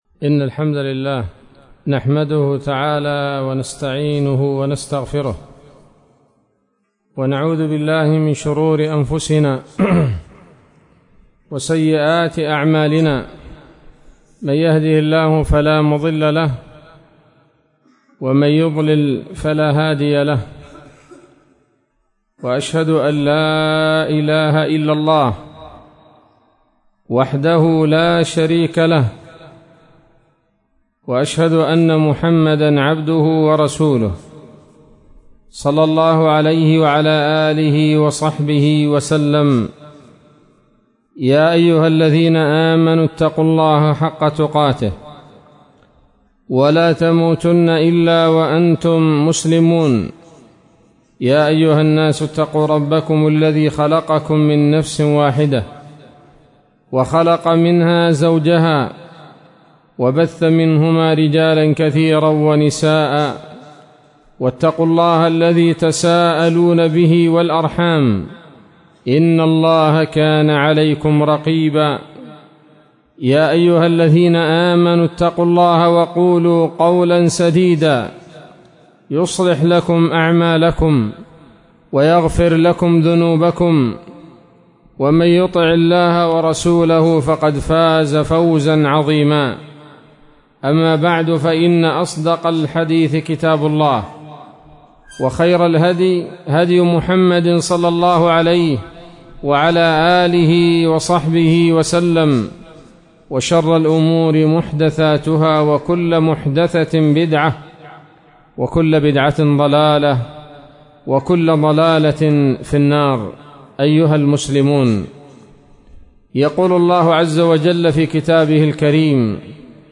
محاضرة قيمة بعنوان: (( أضر فتنة على رجال الأمة )) ليلة السبت 17 ذو الحجة 1443هـ، بمسجد الساحل - كود نمر- البريقة